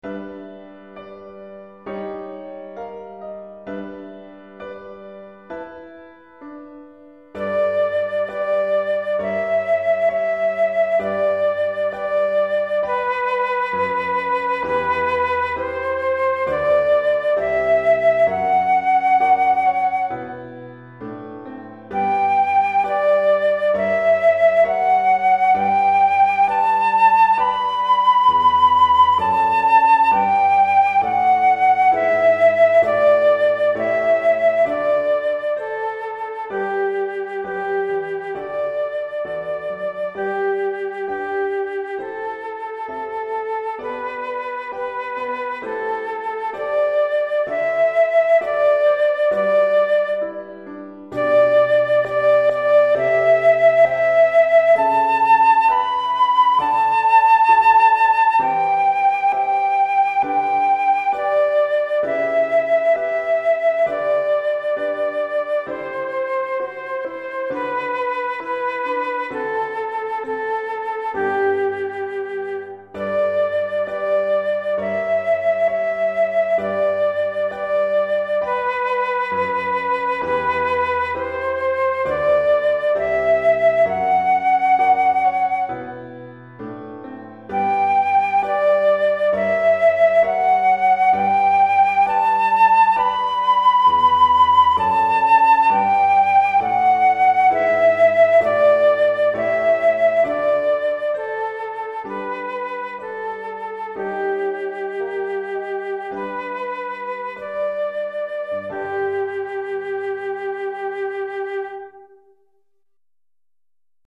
pour flûte et piano DEGRE CYCLE 1
Flûte et piano